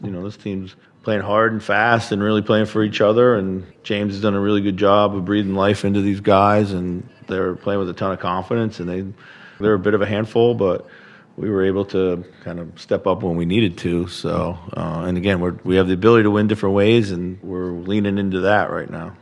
Head coach Chris Finch says beating New Orleans is better than it looks based on the Pelicans 3-20 season record.